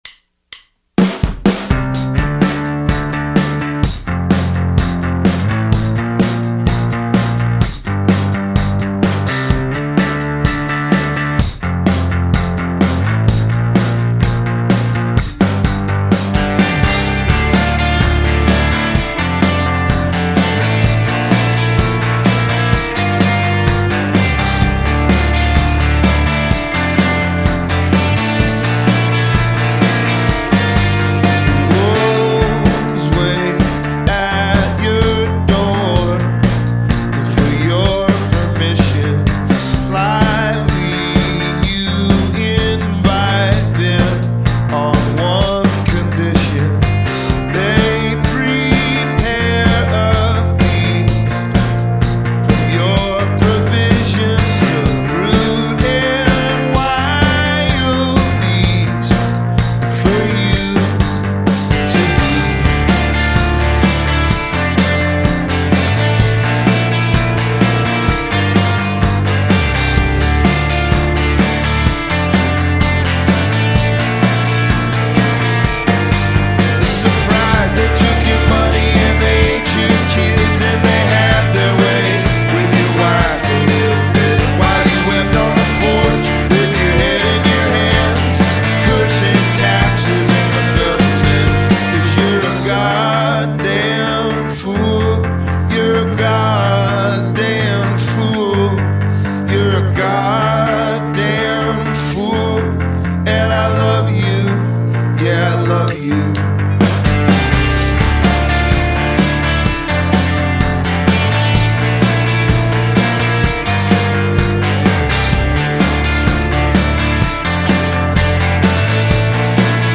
indie-pop